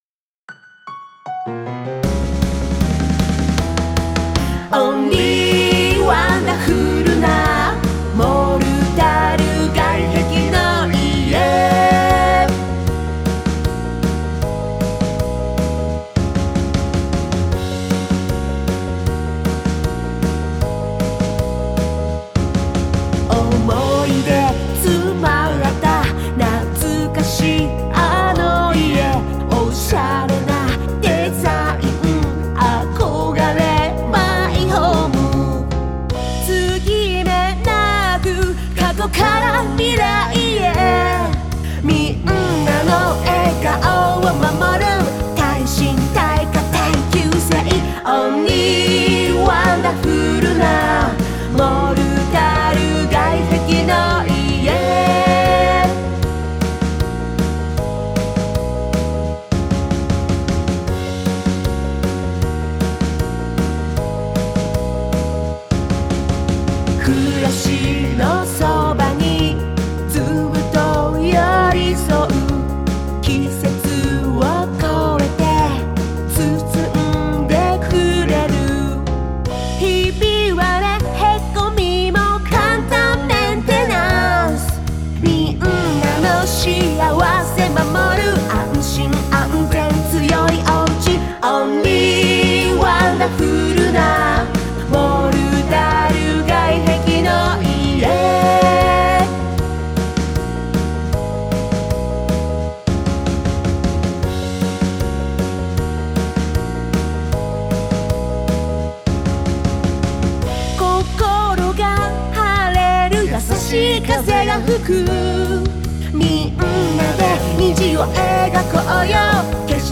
推されるリズムが何度も聞くうちに自然と覚え、口ずさんでしまいそうです。単語をよく選んで使い、モルタル外壁の家のよさを具体的に表現されています。